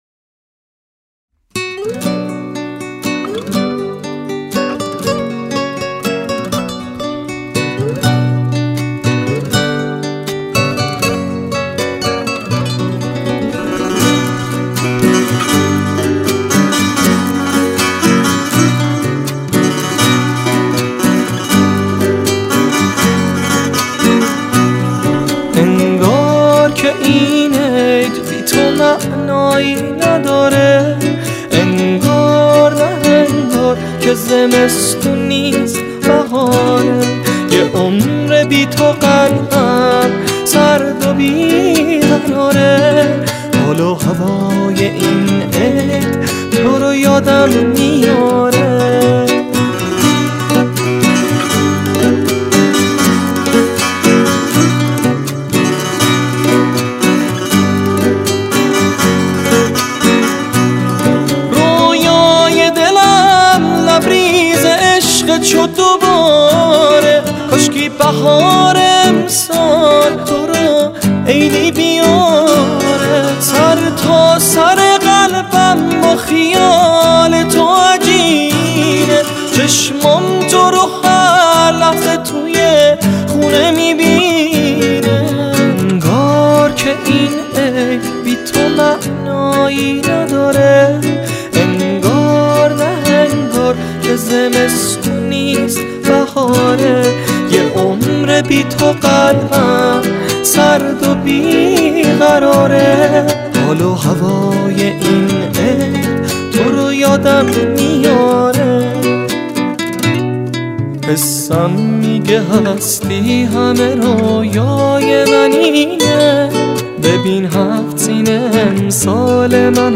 موسیقی
گیتار
سه تار